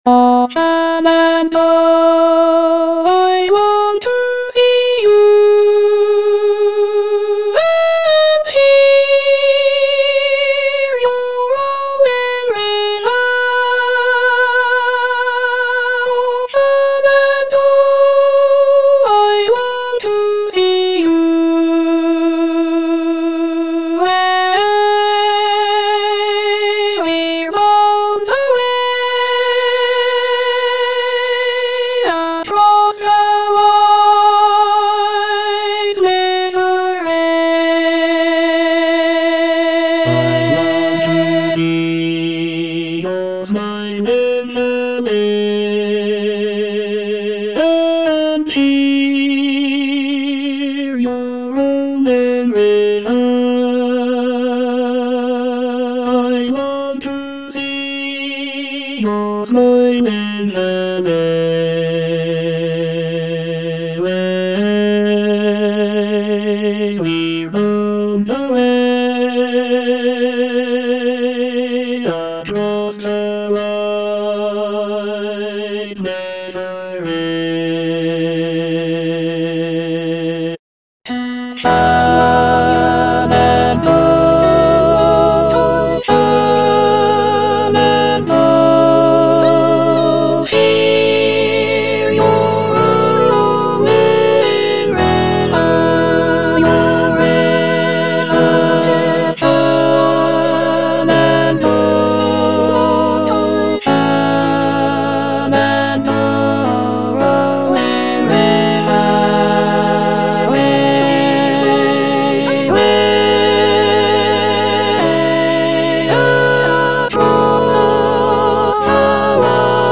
Alto I (Emphasised voice and other voices)